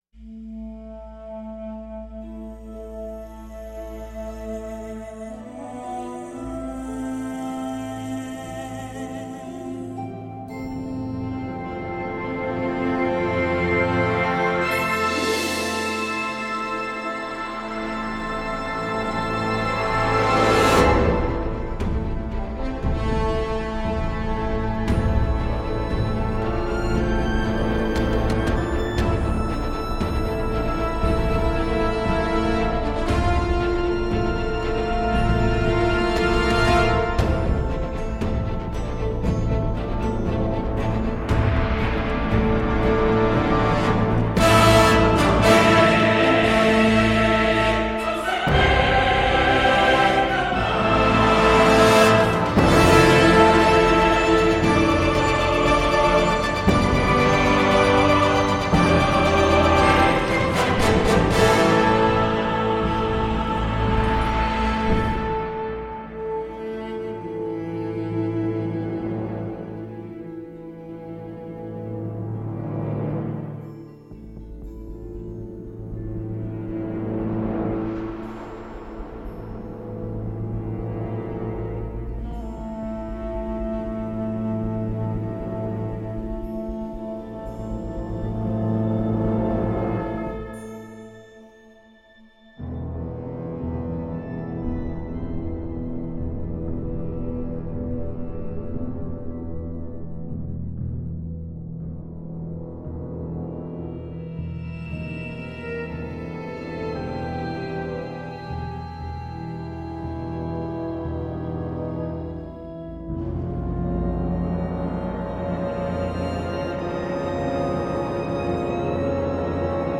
Hélas, le rendu des cuivres ne me plait toujours pas.